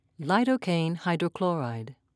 (lye'doe-kane)